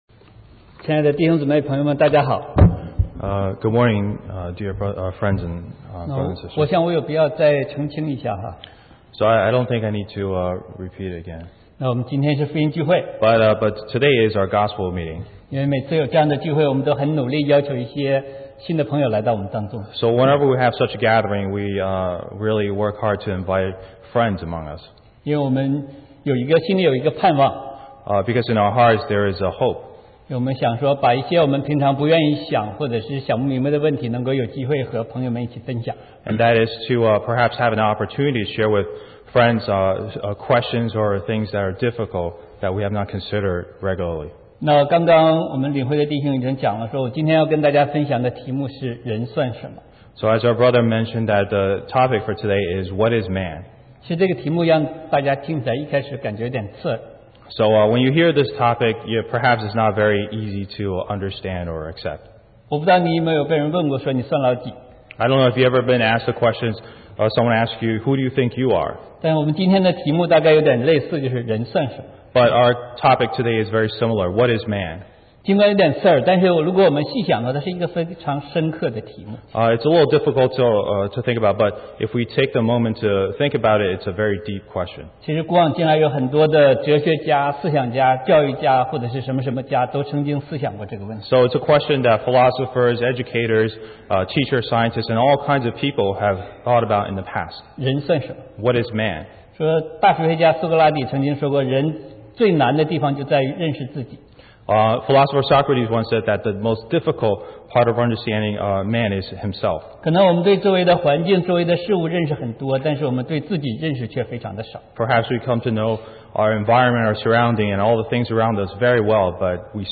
Gospel Meeting